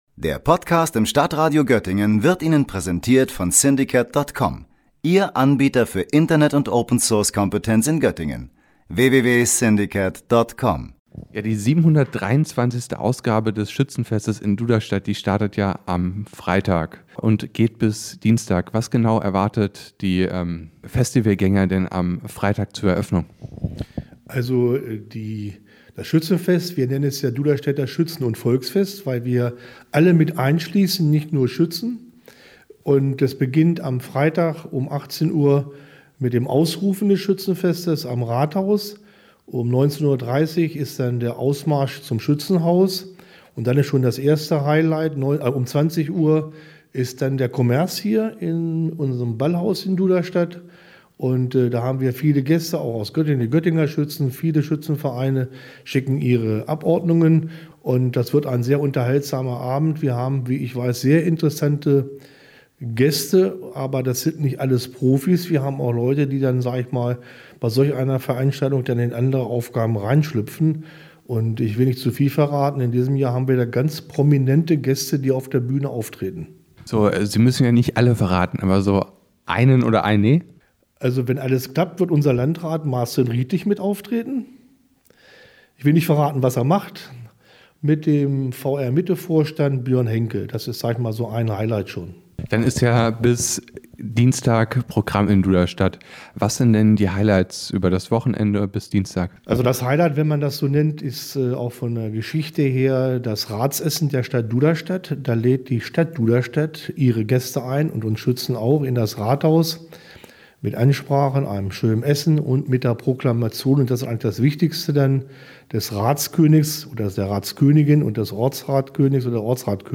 Die beiden haben sich im Duderstädter Schützenhaus getroffen.